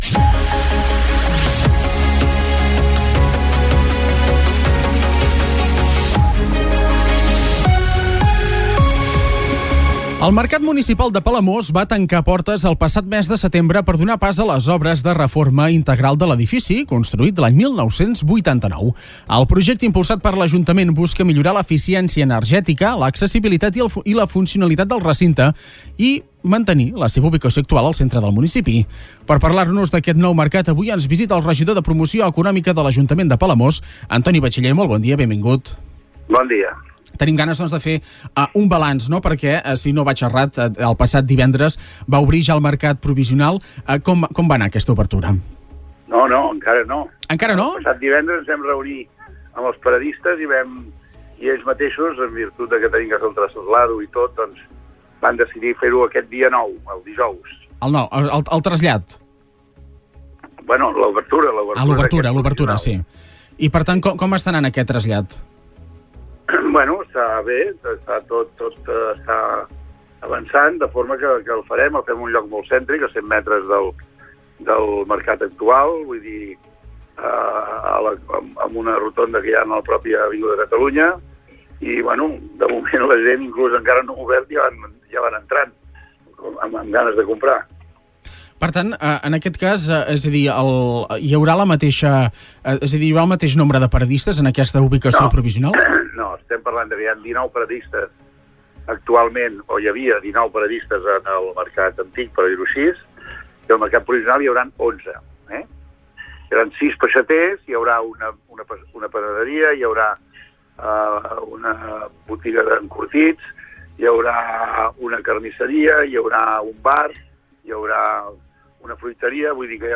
Supermatí - entrevistes
Segons ha explicat el regidor de Promoció Econòmica en una entrevista al Supermatí, Antoni Bachiller, les obres es finançaran amb els crèdits de la subvenció Next Generation i permetran una gran transformació de l’edifici, actualitzant-lo amb noves tecnologies i fent-lo més competitiu econòmicament.